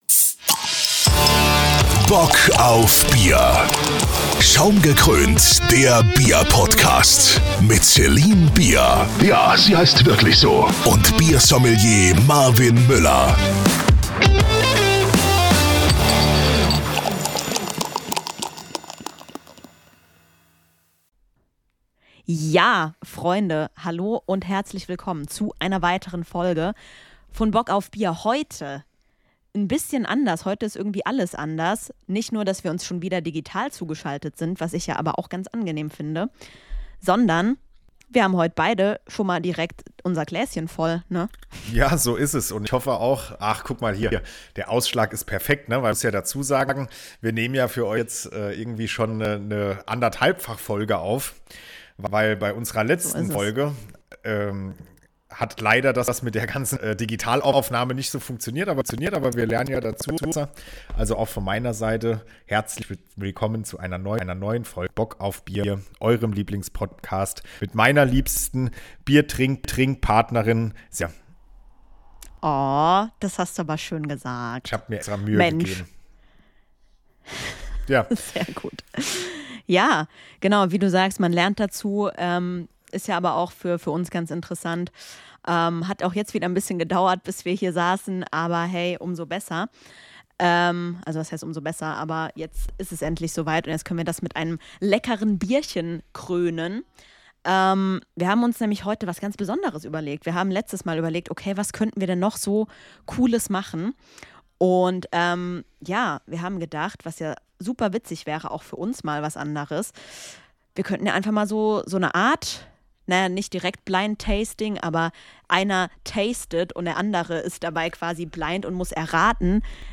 Jeder hat sich ein Bier ausgesucht und die andere Person muss anhand verschiedener Merkmale erraten, um welches Bier es sich handeln könnte. Wir werden das bestimmt in dieser Form öfter machen, also lasst euch von der Audioqualität nicht abschrecken. Cheers und bis zum nächsten Mal - dann auch wieder in besserer Qualität, versproche.